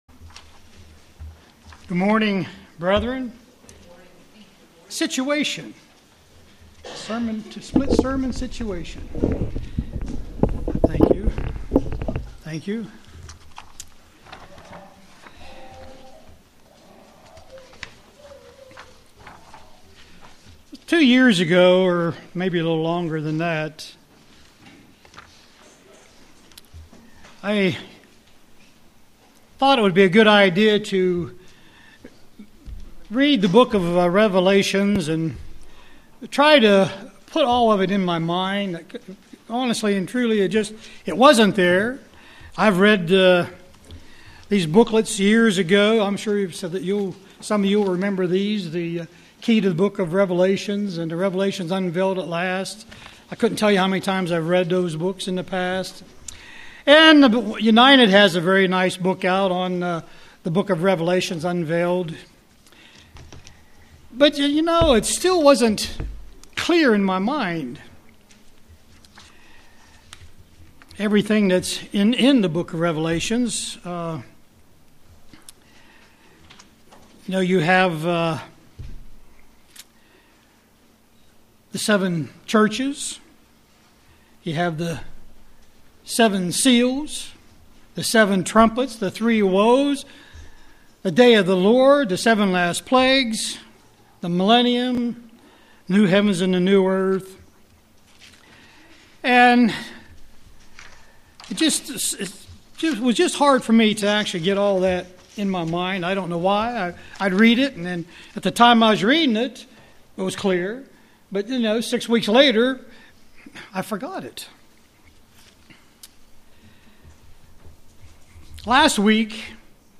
Print Keeping Revelation UCG Sermon